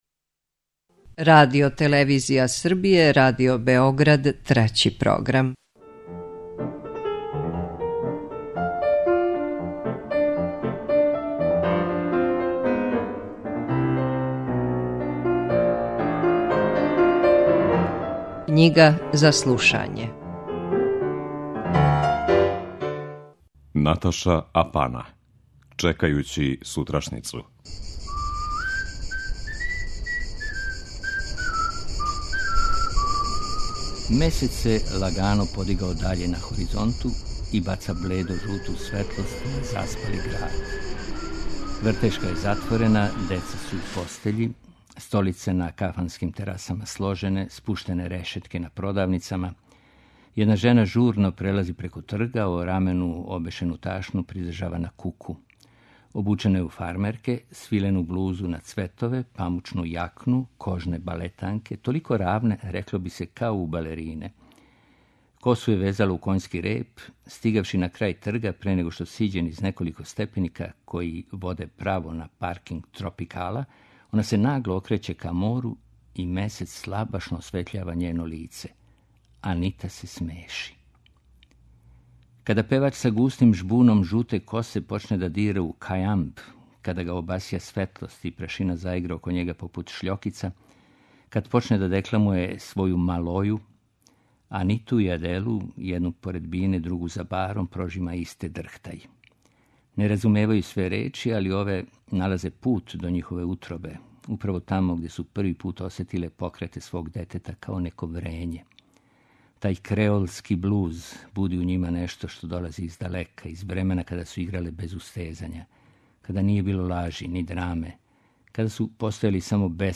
У емисији Књига за слушање можете пратити десети наставак романа „Чекајући сутрашњицу”, који је написала Наташа Апана, француска књижевница пореклом са Маурицијуса.